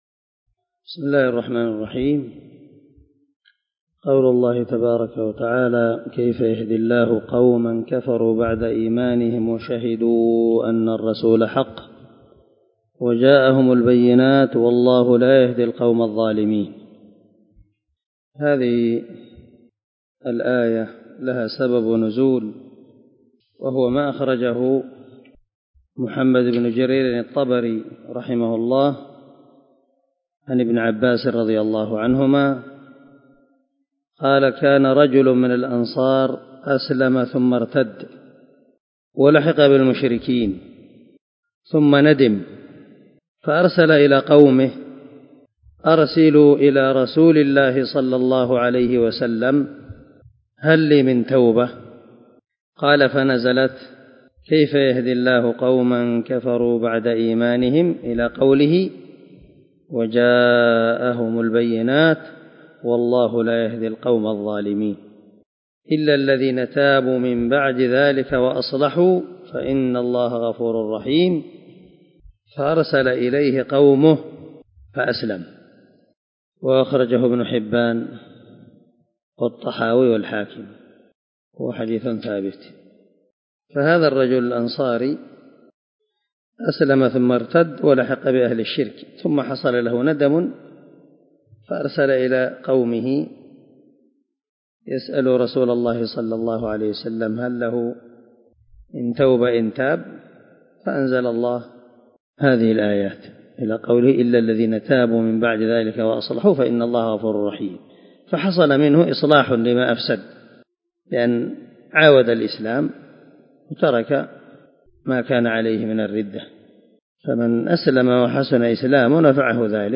182الدرس 27 تابع تفسير آية ( 86 -92 )من سورة آل عمران من تفسير القران الكريم مع قراءة لتفسير السعدي
دار الحديث- المَحاوِلة- الصبيحة.